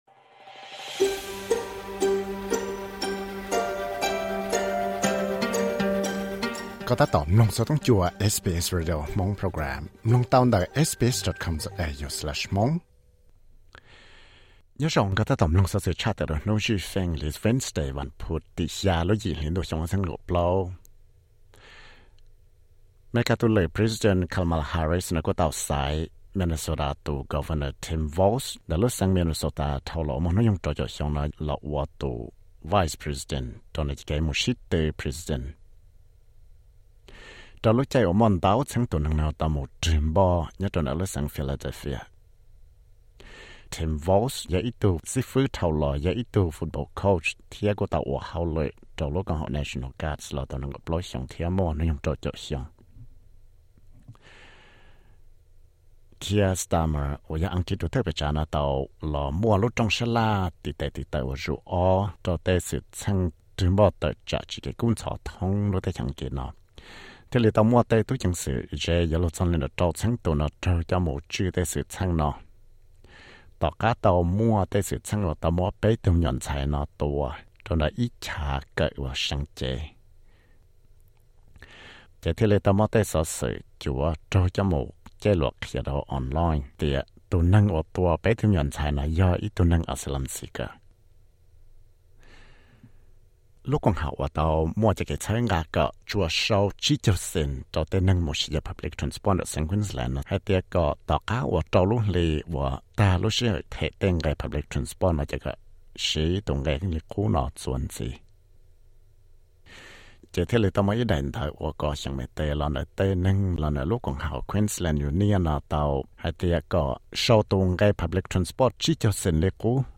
Xov xwm luv Credit